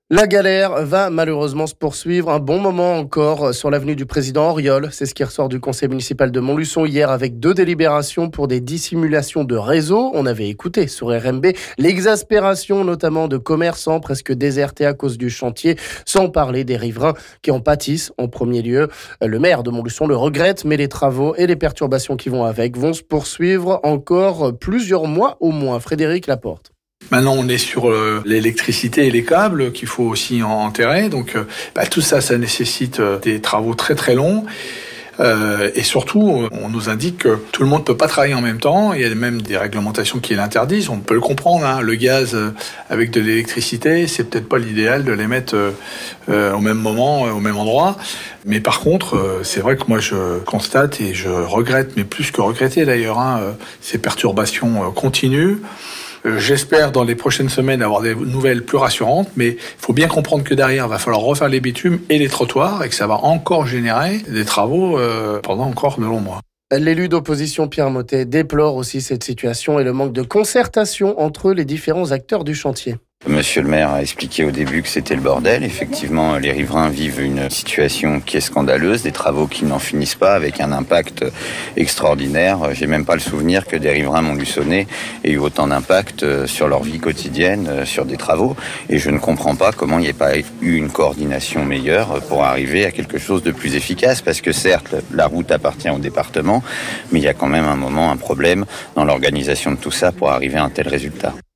On écoute ici le maire Frédéric Laporte et l'élu d'opposition Pierre Mothet...